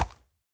minecraft / sounds / mob / horse / soft4.ogg